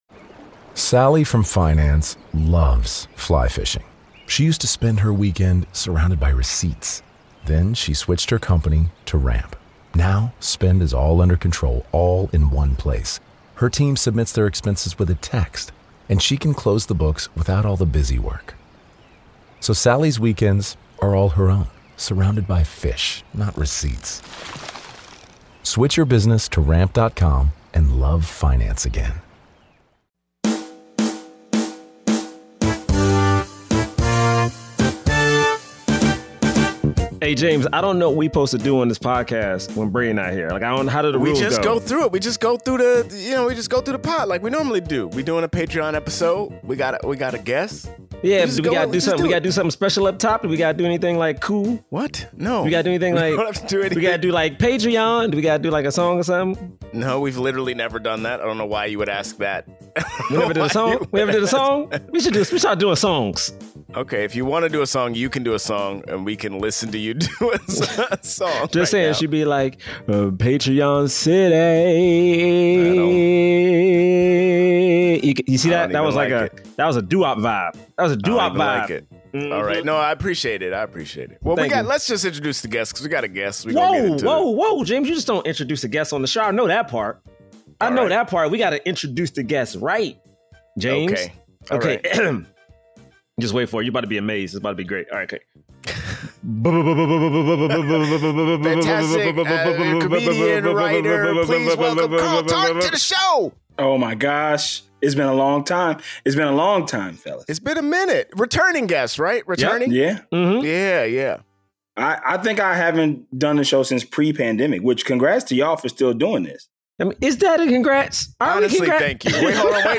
Black Men Can't Jump [In Hollywood] is a comedic podcast that reviews films with leading actors of color and analyzes them in the context of race and Hollywood's diversity issues.